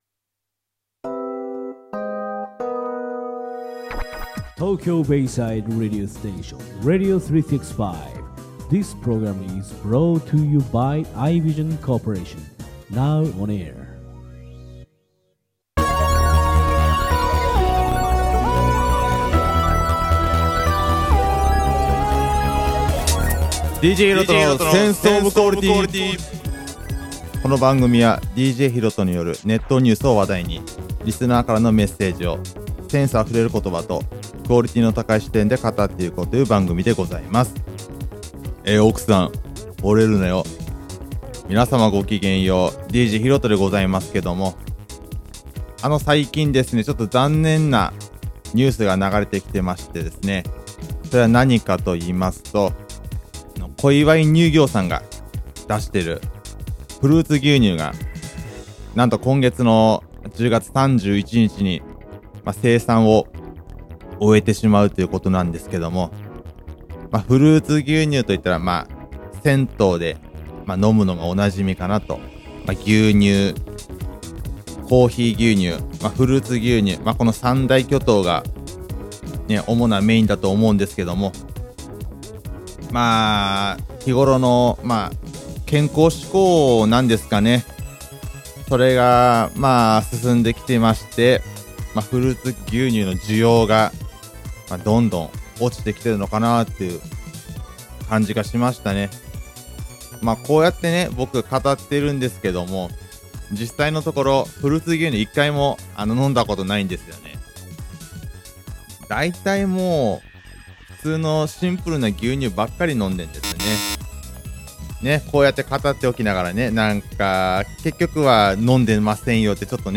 メールの流れから、お台場警察24時・・・というより、お台場モノマネ24時みたいな感じになりました。